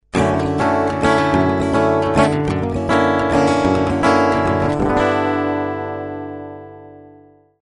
Extraits sonores
Caractéristiques Matériaux: Bois : le dugain original pour ses qualités naturelles anti dérapante et les sons les plus chauds. Plus le bois est tendre, plus le son est chaud mais le taux d'usure est plus grand.